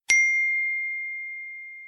Category: Messages Ringtones